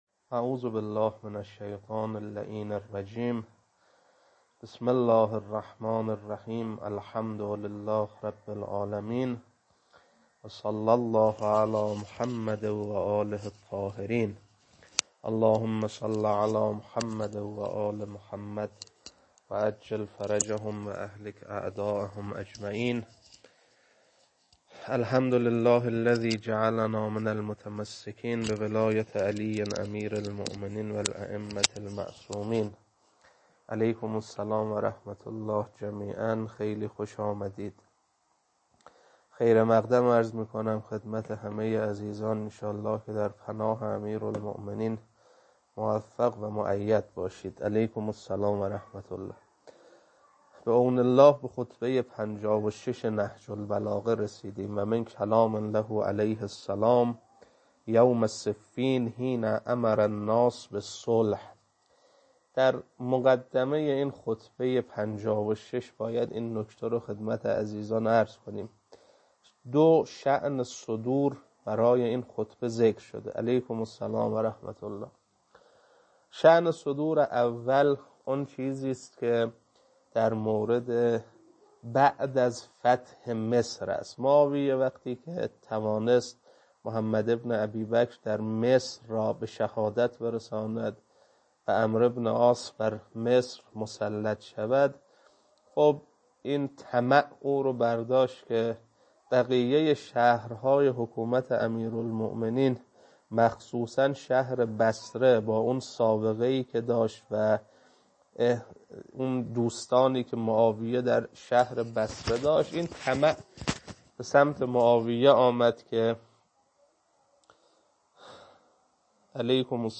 خطبه-56.mp3